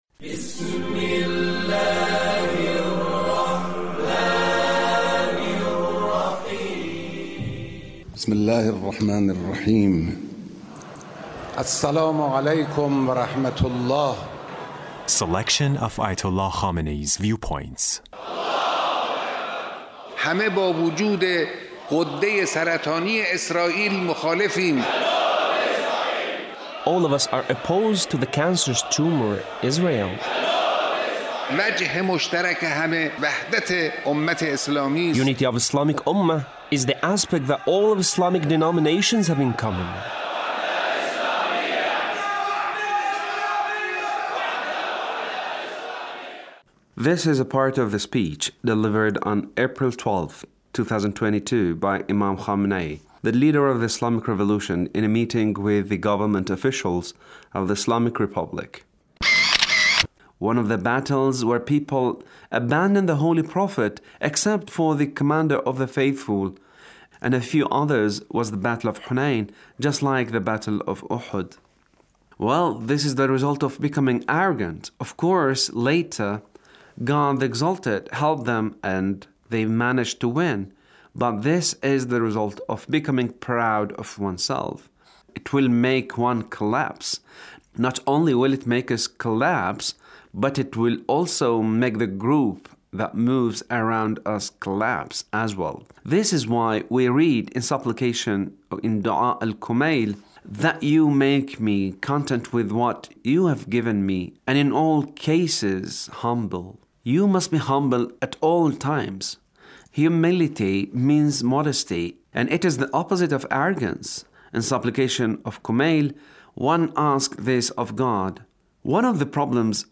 Leader's speech (1380)
The Leader's speech on Ramadan